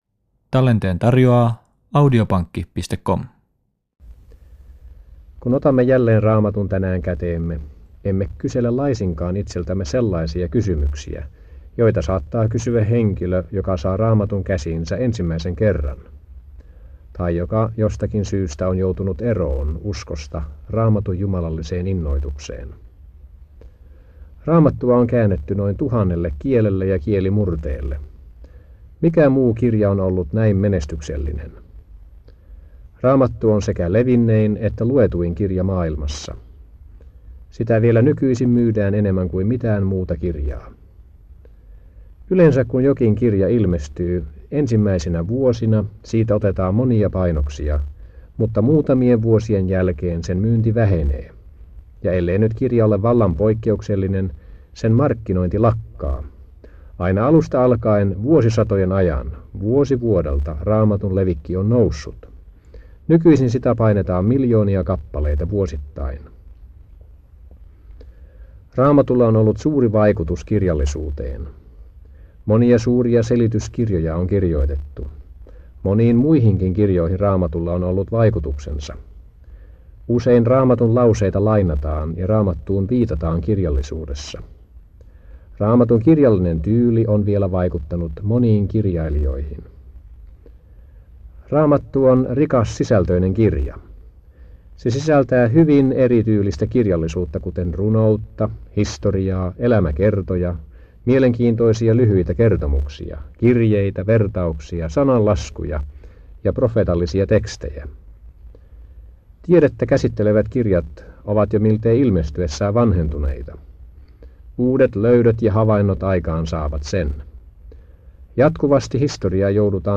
Tampereella 1980-luvulla https